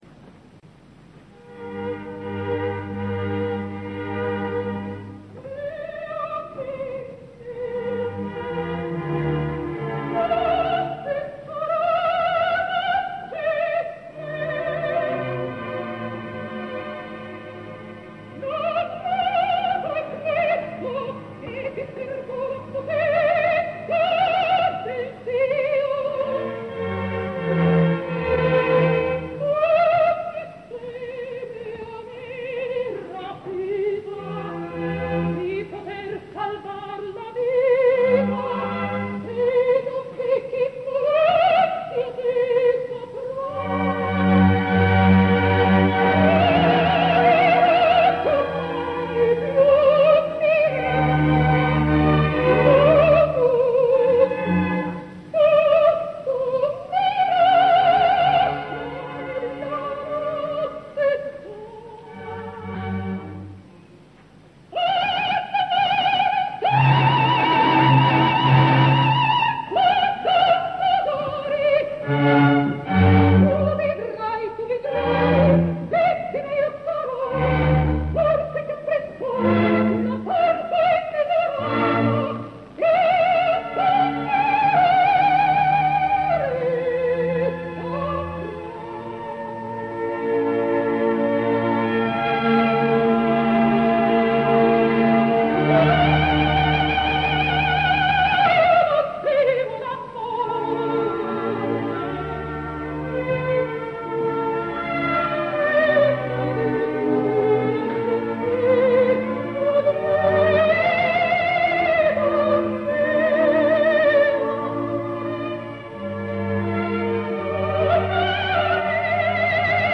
Edizione ? del 1956, opera completa, registrazione dal vivo.
Orchestra del Teatro alla Scala di Milanodiretta da Carlo Maria Giulini.